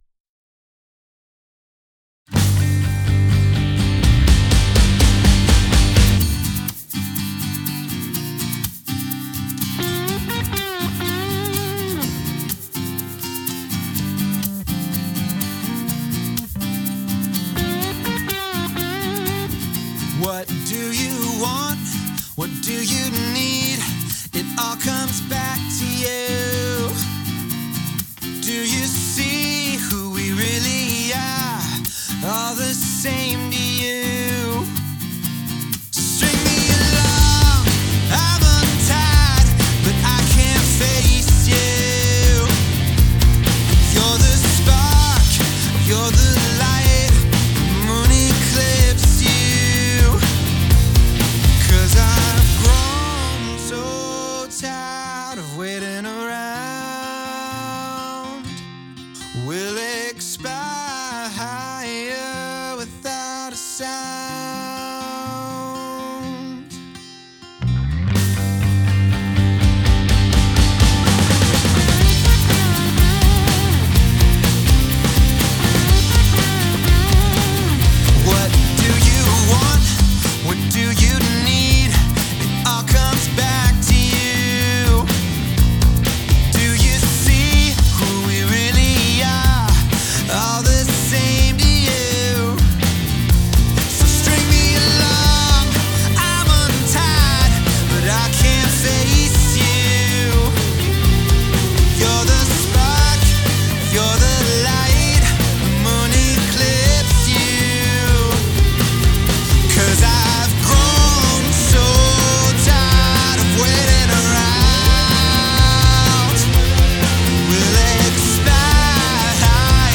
Gullfoss Master nochmal über das obige Render gezogen und griff genau da ein wo ich noch unzufrieden war in den tieferen Mitten und Bass, Drums kommen knackiger und insgesamt klingts stabiler.